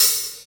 Index of /90_sSampleCDs/Roland L-CDX-01/KIT_Drum Kits 8/KIT_Pointy Kit
HAT ROOM H0A.wav